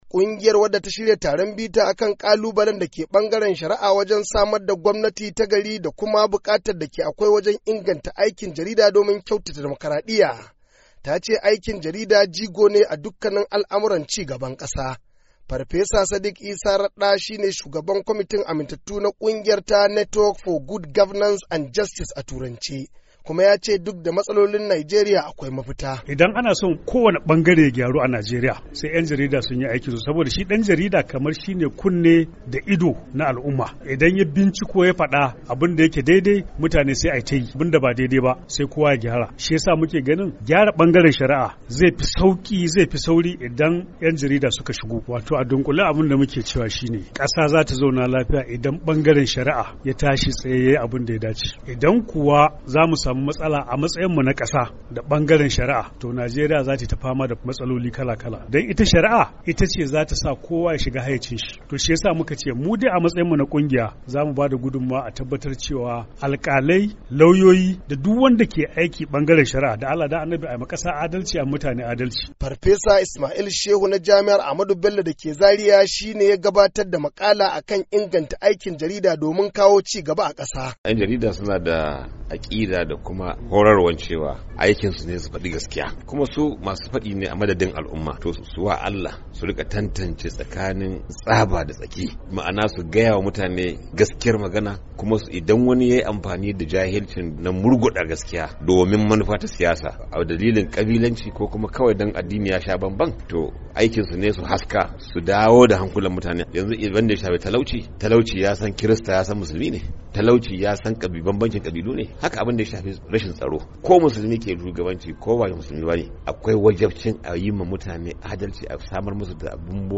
KADUNA, NIGERIA - Kungiyar wadda ta shirya taron bita kan kalubalen da ke bangaren shari'a wajen samar da gwamnati ta gari da kuma bukatar inganta aikin jarida don kyautata damakadiyya ta ce aikin jarida jigo ne a dukkan lamurran ci gaban kasa.
Saurari rahoto cikin sauti